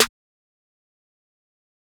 Snares
Young Metro Snare.wav